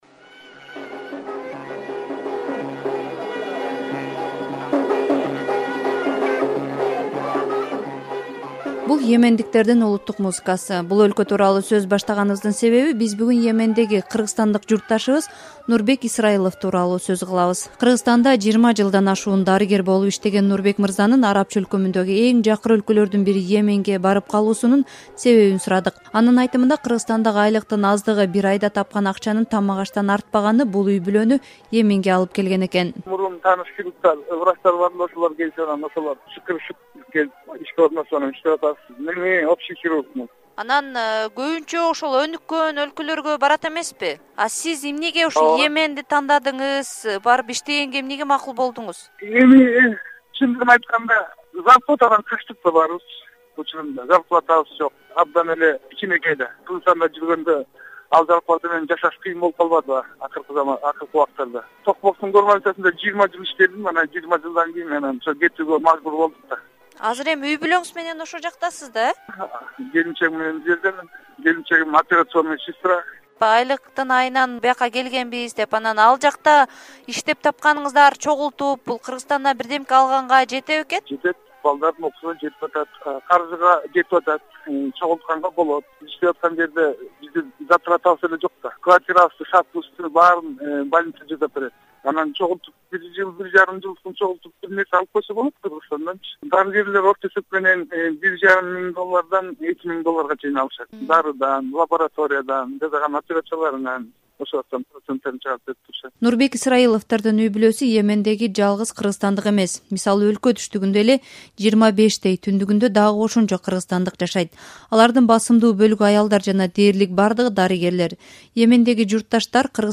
Ал “Азаттыкка” маек куруп, ал жактагы кыргыздар, жашоо-турмушу, жергиликтүү тургундардын салт-санаасындагы өзгөчөлүгү туурасында айтып берди.